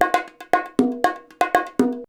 119BONG10.wav